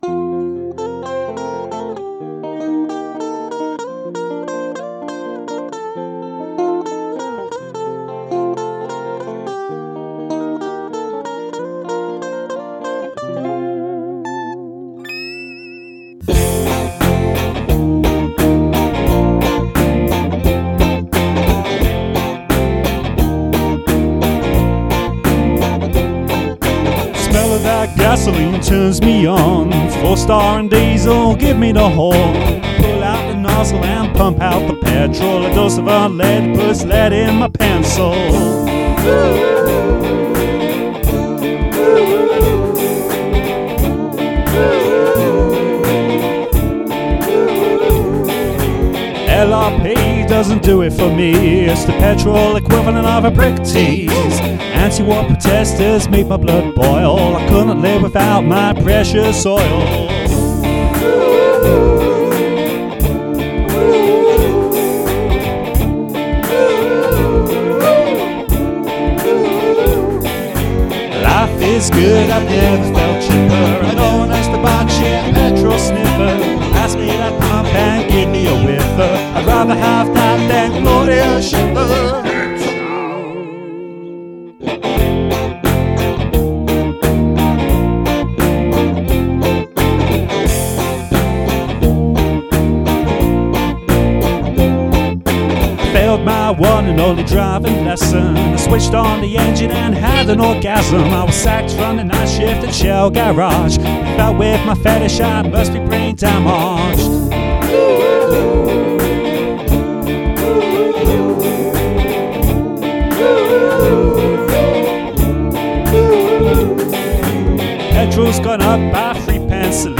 for the 'oom-pah' rhythms and Panda Pop chord sequence.